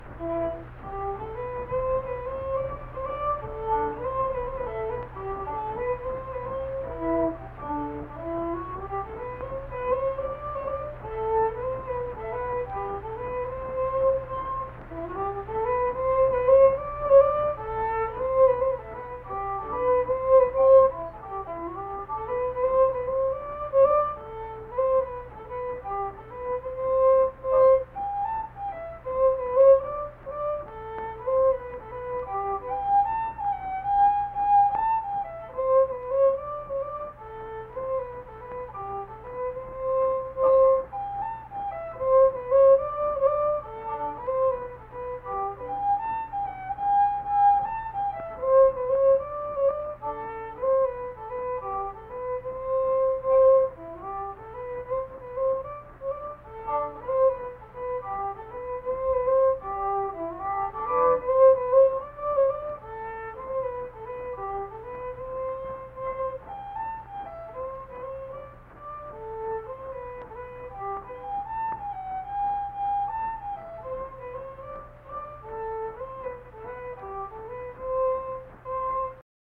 Unaccompanied fiddle music performance
Instrumental Music
Fiddle